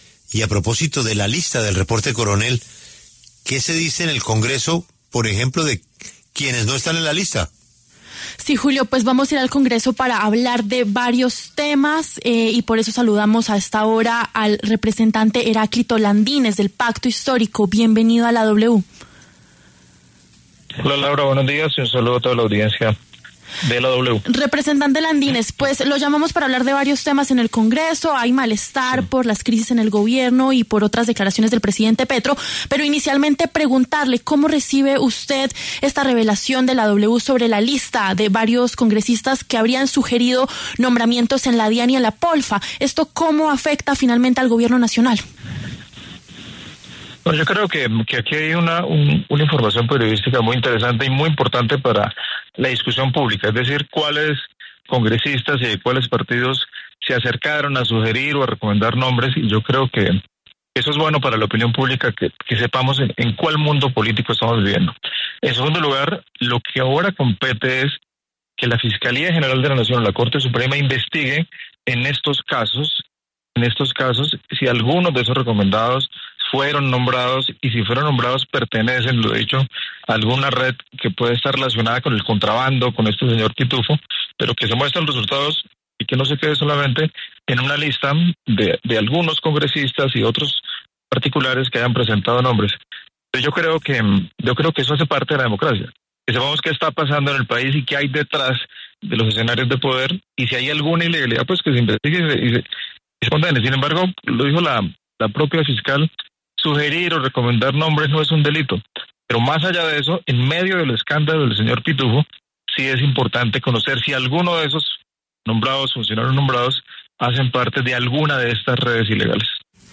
Los congresistas Heráclito Landínez, del Pacto Histórico, y Carlos Fernando Motoa, de Cambio Radical, pasaron por los micrófonos de La W.